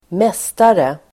Uttal: [²m'es:tare]